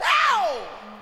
Vox [ Wow ].wav